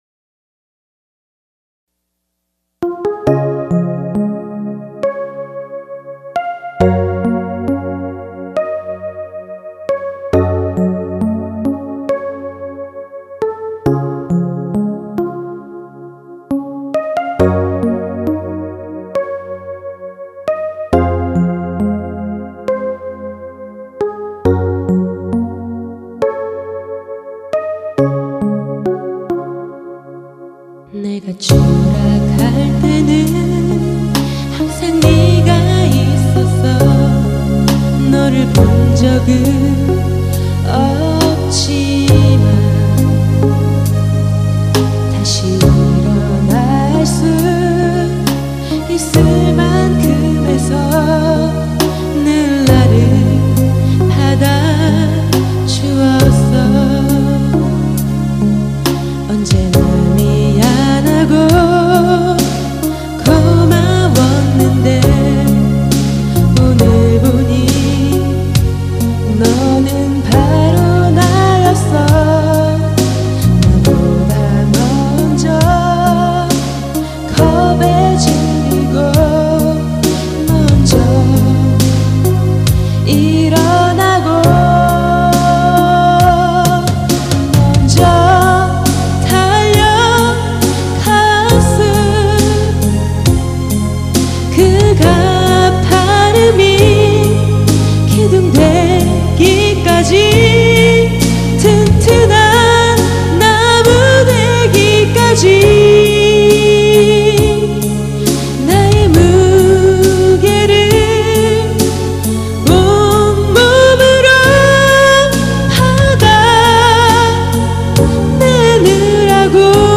연극배우, 시노래 가수.